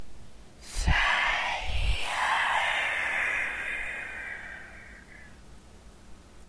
恐怖片 " 令人毛骨悚然的低沉声音
描述：怪物的令人毛骨悚然的声音效果。
Tag: 爬行 语音